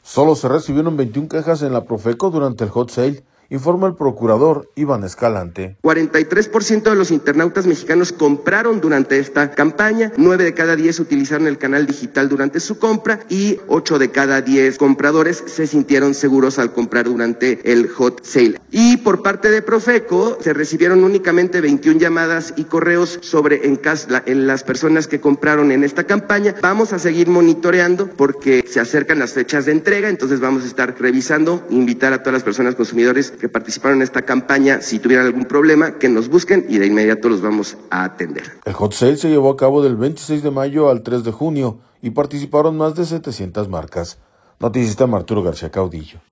Solo se recibieron 21 quejas en la Profeco durante en Hot Sale, informa el Procurador Iván Escalante.